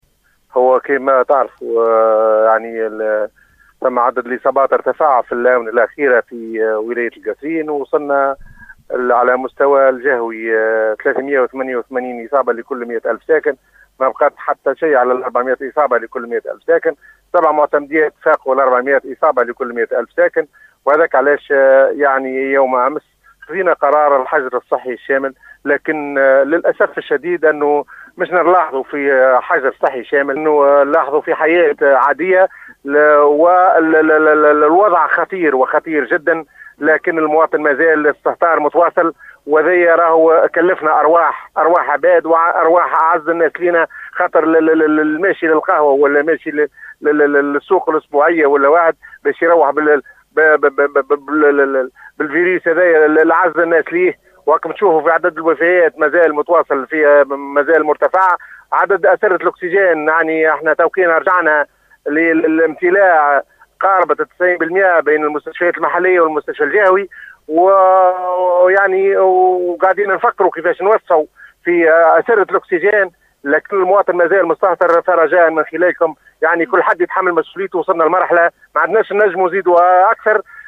اكد المدير الجهوي للصحة بالقصرين الدكتور عبد الغني الشعباني صباح اليوم خلال مداخلته ببرنامج نهارك زين ان عدد الاصابات بالجهة ارتفع الي 388 اصابة لكل 100000 ساكن كما تجاوزت 7 معتمديات بالقصرين عدد الاصابات 400 اصابة .مما فرض إقرار الحجر الصحي الشامل بكامل الولاية .